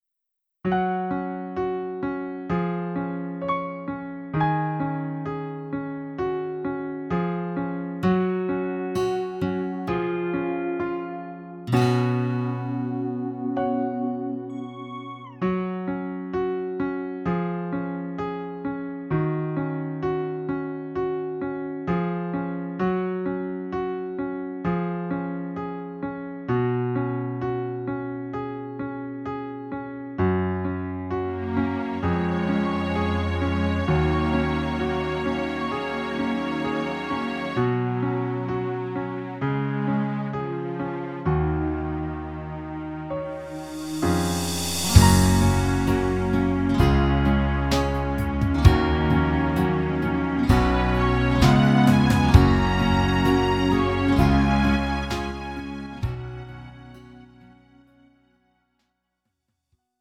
음정 원키 3:09
장르 가요 구분 Lite MR